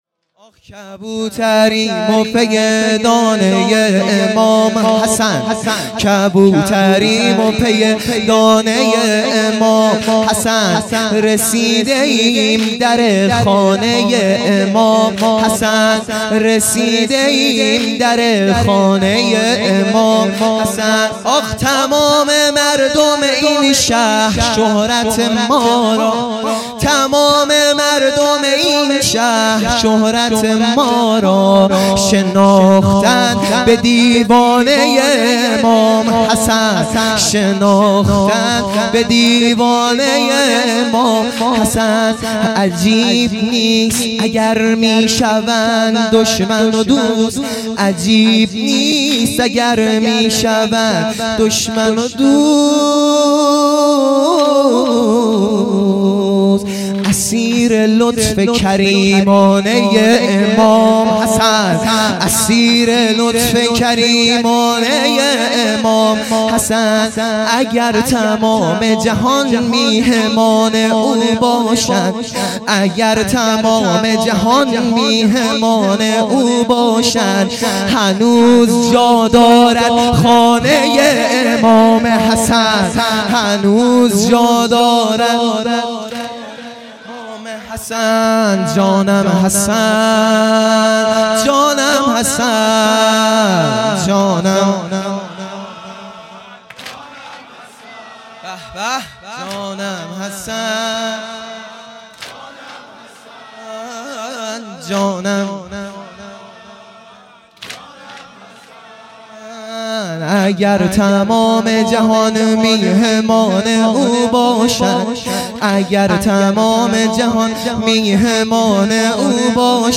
دهه آخر صفر | شب دوم